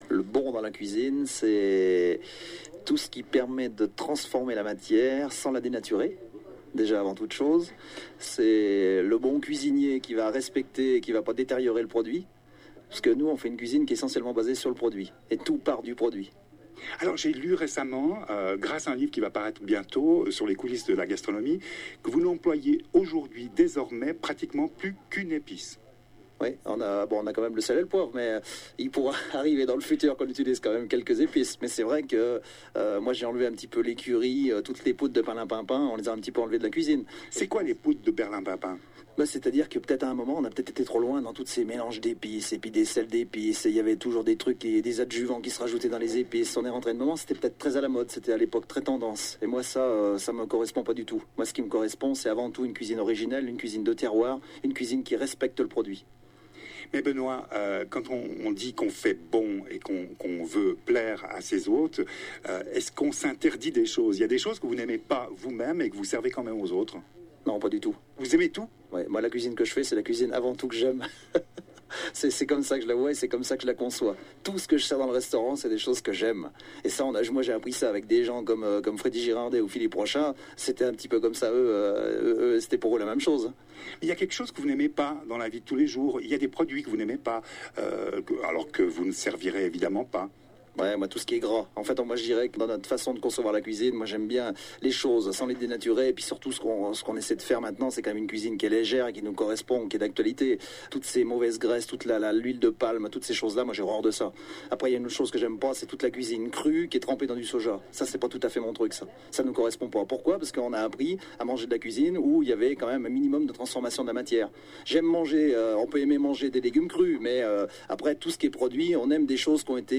L’intégralité de cet entretien